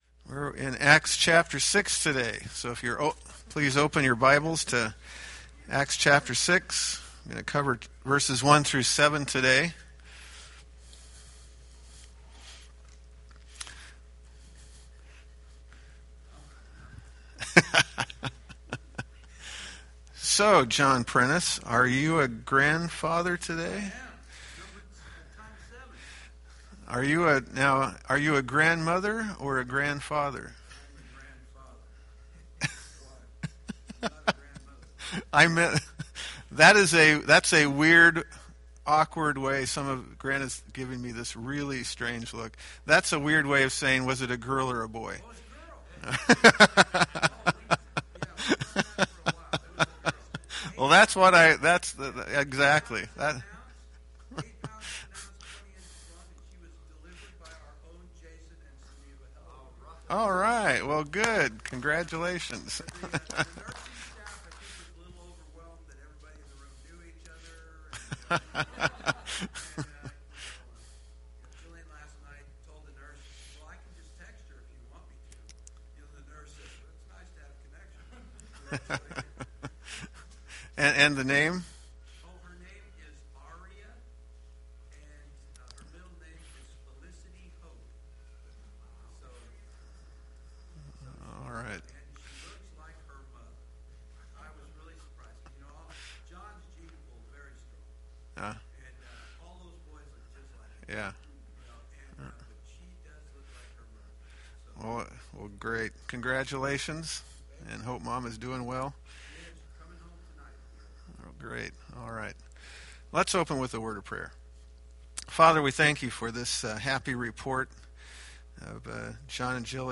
Acts Class - Week 14